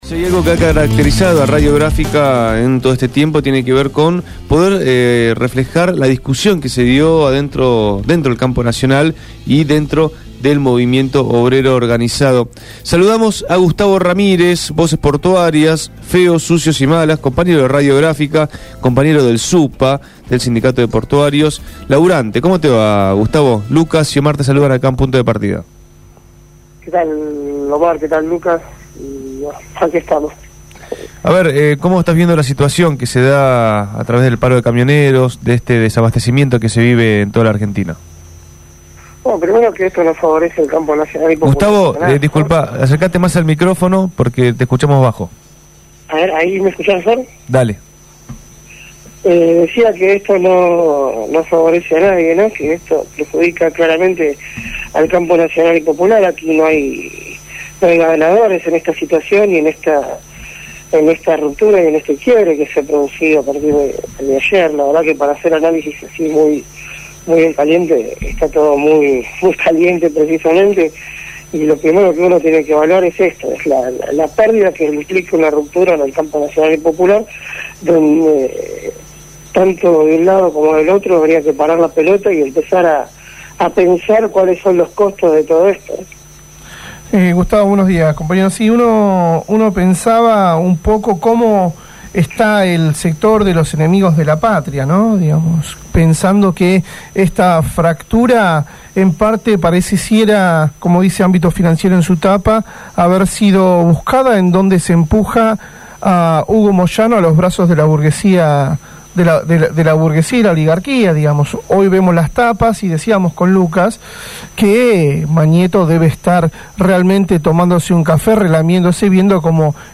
en entrevista con Punto de Partida.